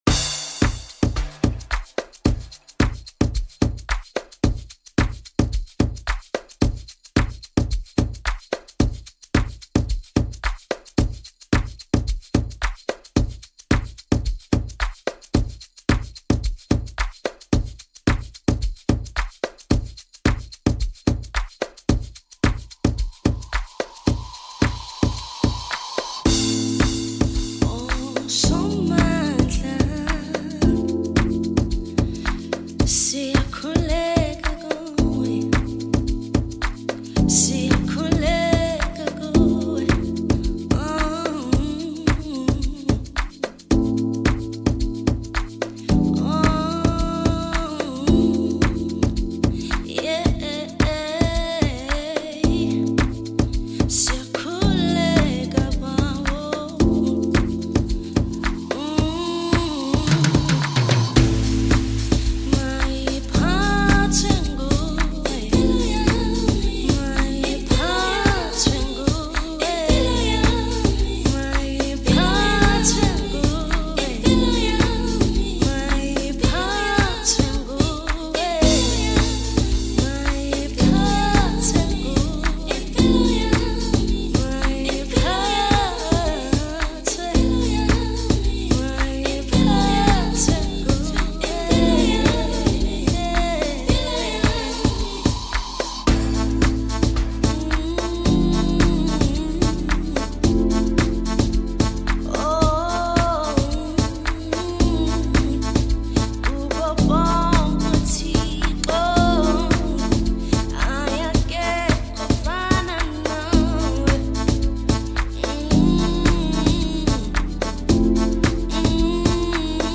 The song features a Soulful production
very smooth vocals